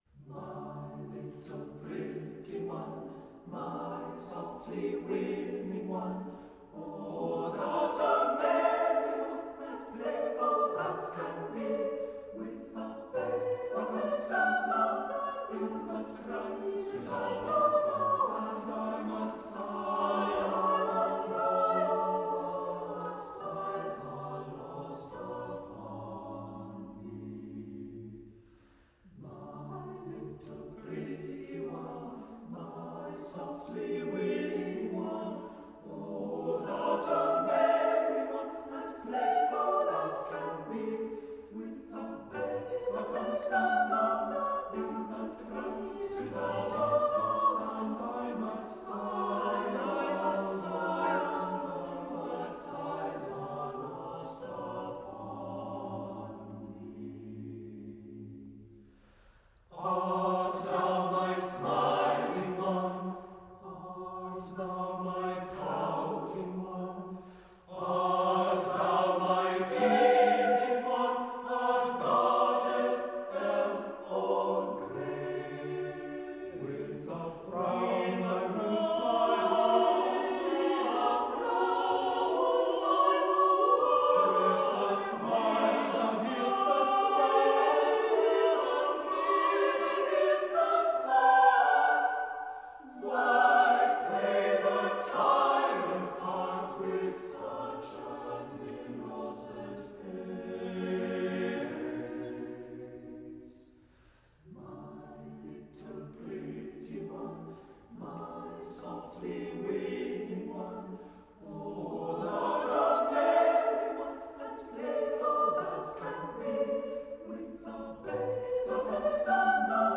accomplished chamber choir